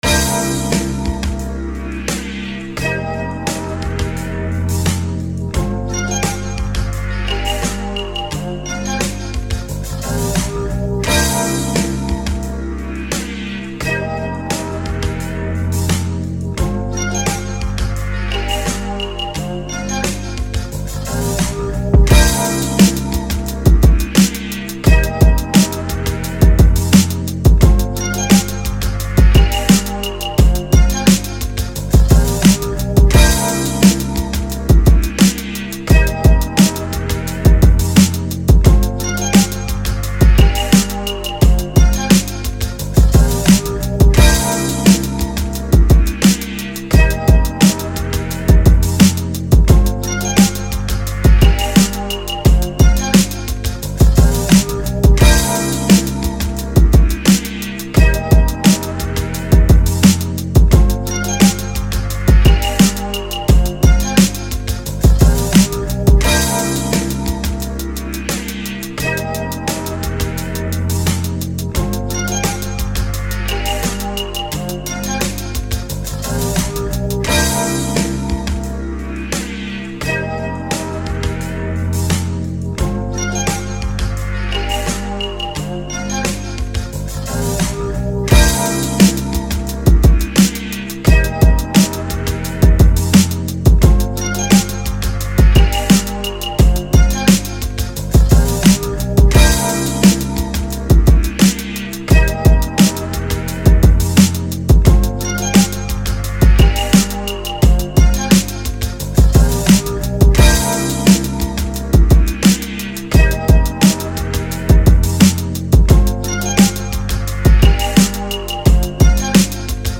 kjøp hip-hop / rap Beats